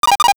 NOTIFICATION_8bit_14_mono.wav